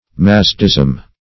Mazdeism \Maz"de*ism\, n. The Zoroastrian religion.